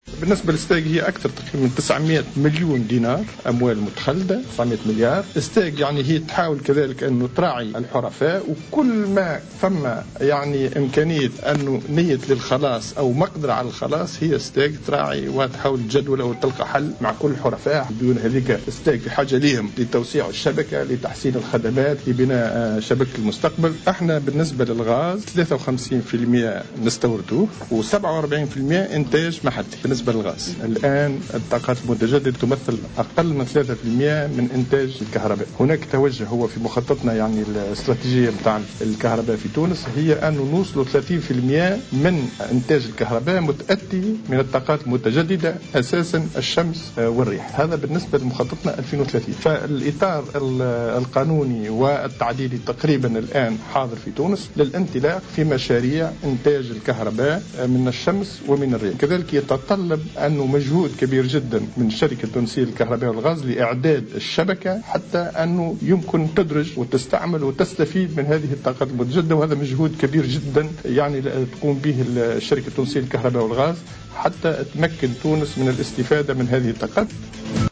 بلغت ديون الشركة التونسية للكهرباء والغاز المتخلدة بذمة حرفائها 900 مليون دينار، وفق ما أكده وزير الطاقة والمناجم منجي مرزوق في تصريح ل"الجوهرة أف أم".